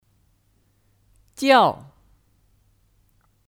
叫 (Jiào 叫)